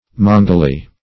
Definition of mangily.
mangily.mp3